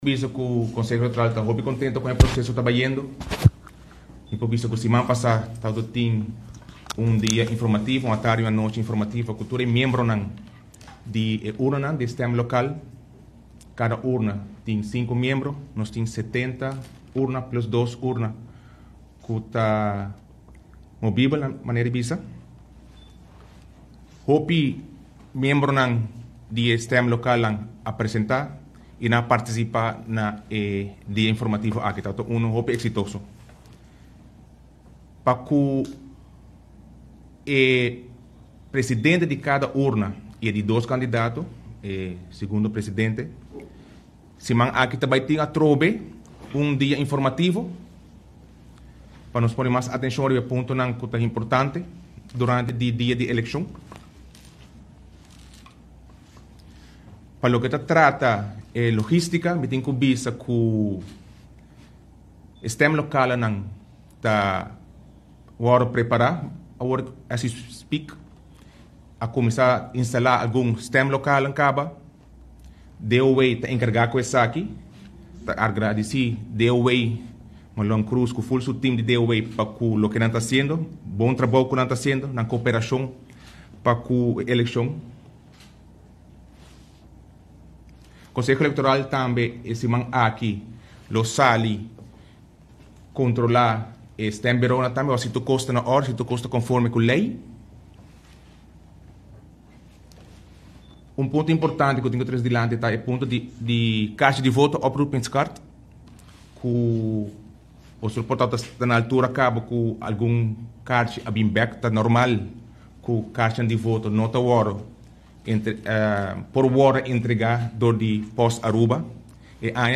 Presidente di Electorale Raad a yama un conferencia di prensa pa asina duna algun informacion riba e eleccion politico cu lo tuma luga diabierna awo.